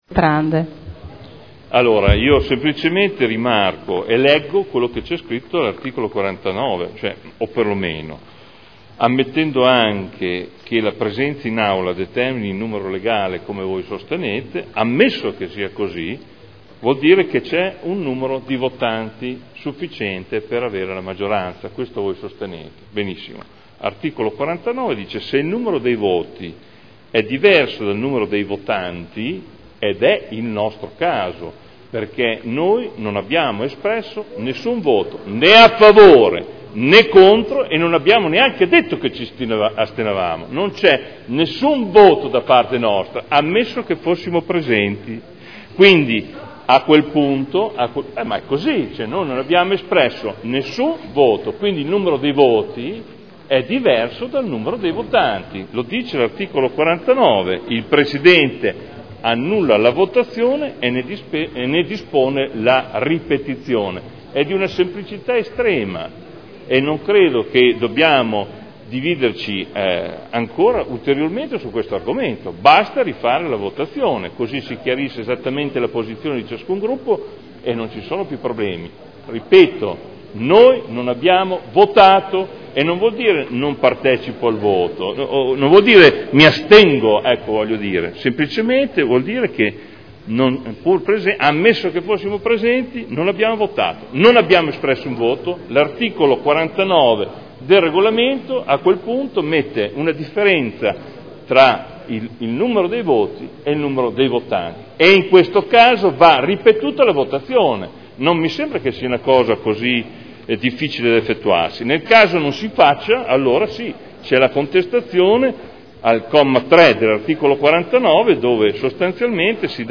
Adolfo Morandi — Sito Audio Consiglio Comunale
Seduta 12/09/2011. Interviene a favore della mozione d'ordine di Ballestrazzi.